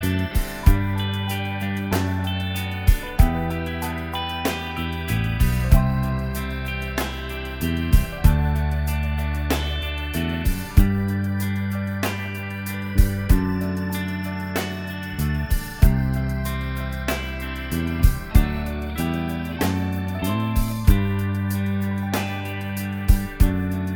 Minus Solo Guitar Soft Rock 3:41 Buy £1.50